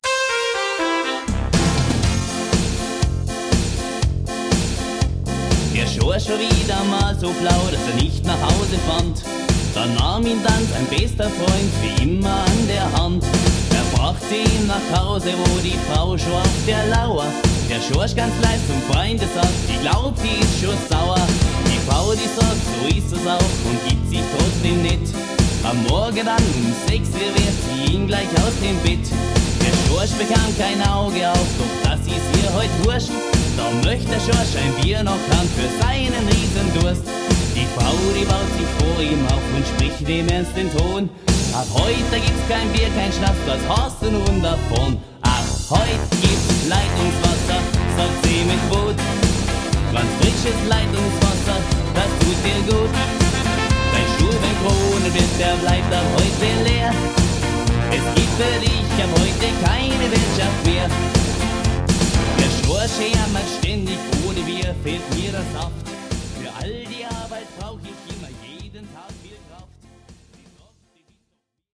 Ab heut gibt's Leitungswasser (Volkstümlich)